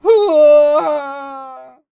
Action SFX Vocal Kit " Male Fall Death 02
描述：用嘴记录
Tag: 尖叫 声音 脱落 坠落 人声